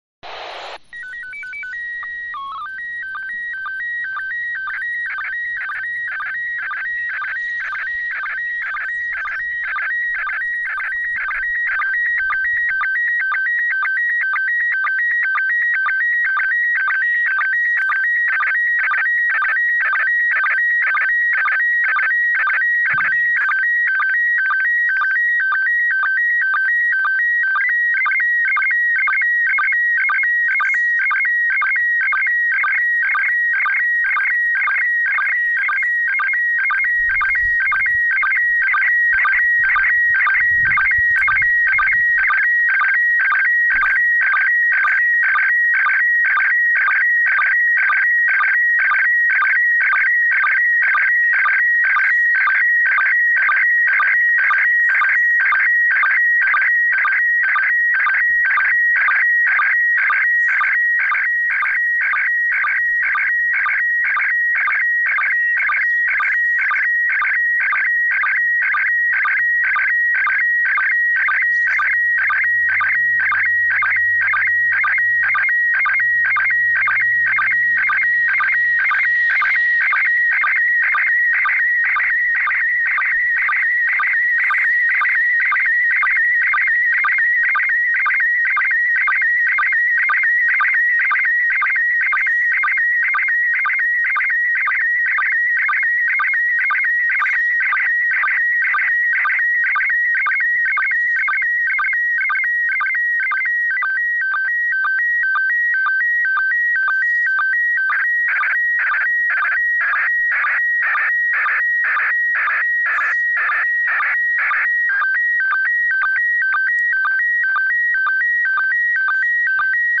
I included the sound files for each image so you can hear the SSTV tones that were transmitted.